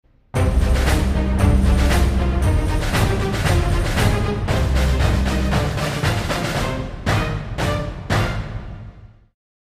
Супергеройская короткая мелодия